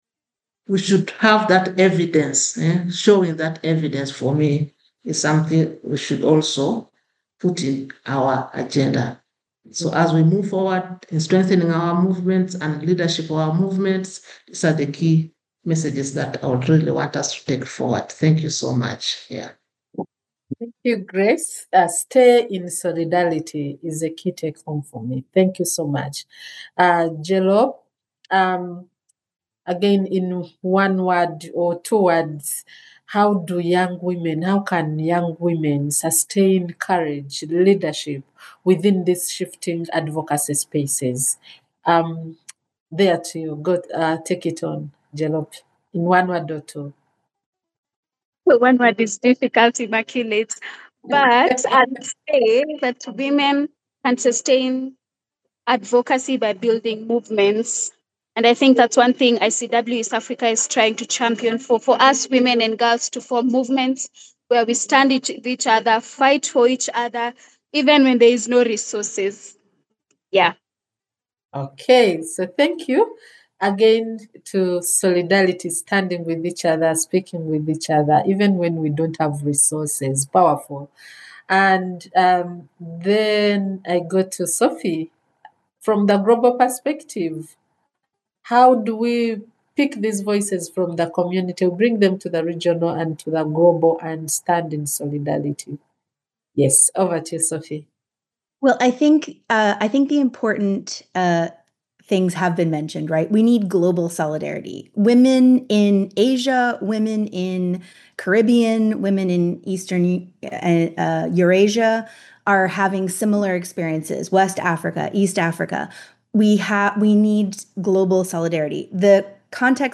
Webinar: Shifts in Ending New and Evolving Forms of Violence Part 5 – International Community of Women living with HIV Eastern Africa